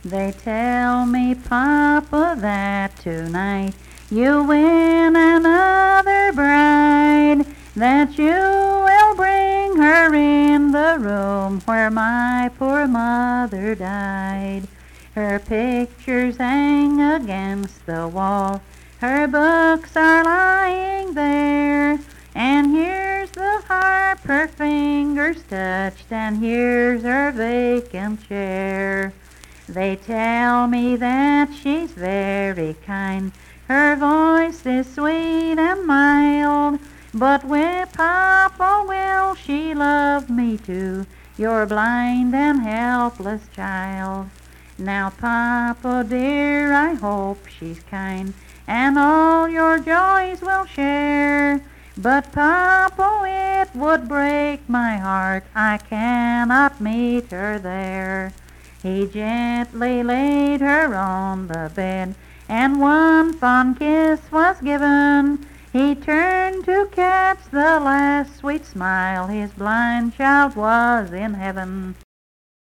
Unaccompanied vocal music performance
Verse-refrain 5(4).
Voice (sung)